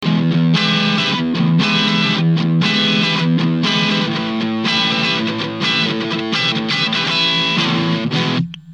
single bridge
TS9_single_bridge.mp3